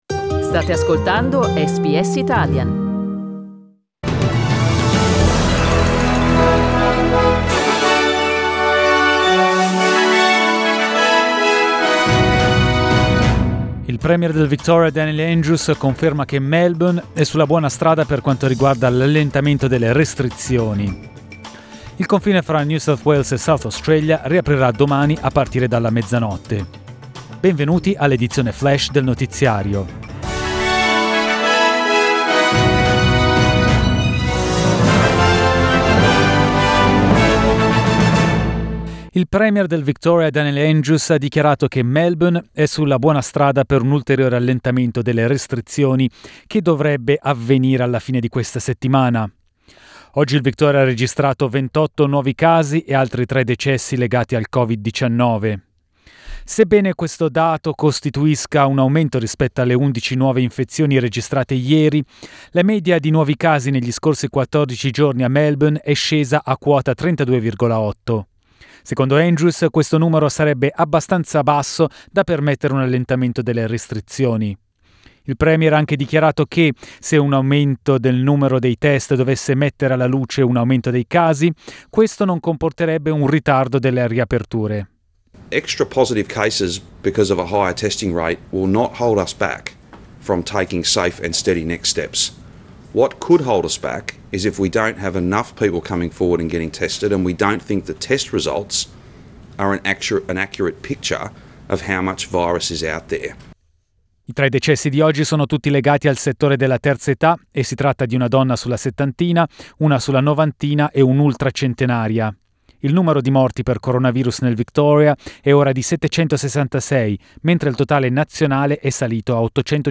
Italian News Flash Tuesday 22 September 2020